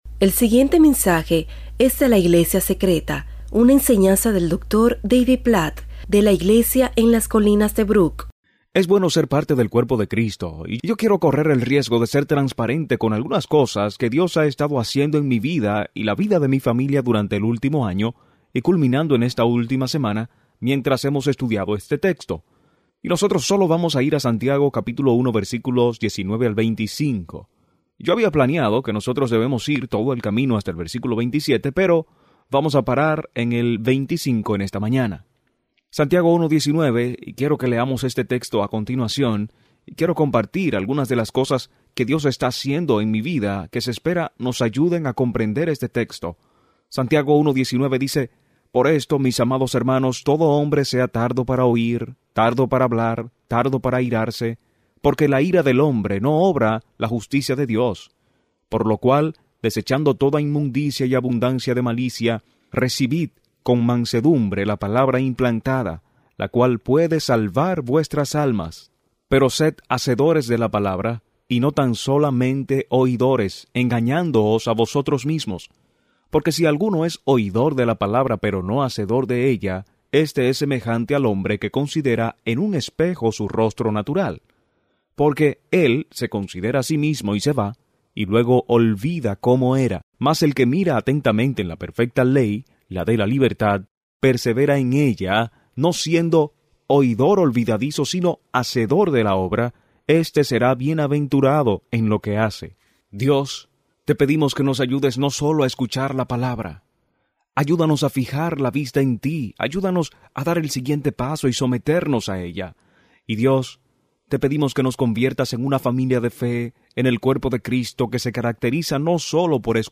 En este mensaje sobre Santiago 1:19–25, el pastor David Platt anima a los cristianos a pasar de solo escuchar a obedecer la Palabra.